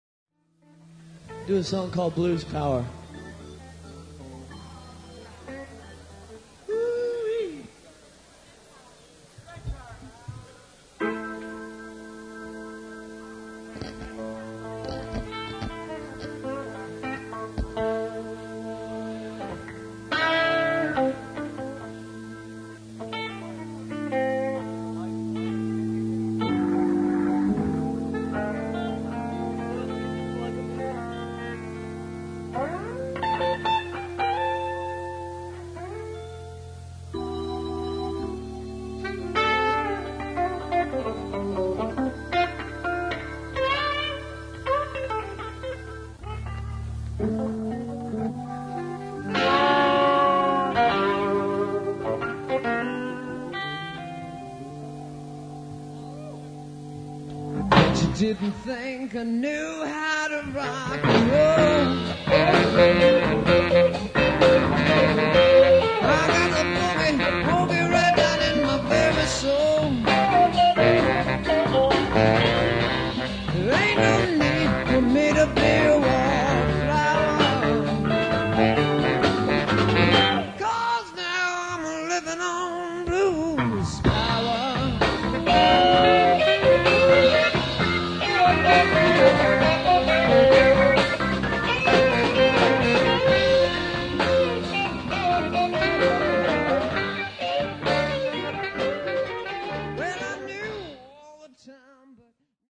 bass
congas
tenor sax , flute and keys
I played guitar, keys, drums, vocals